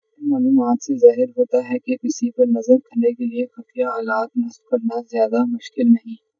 deepfake_detection_dataset_urdu / Spoofed_Tacotron /Speaker_02 /114.wav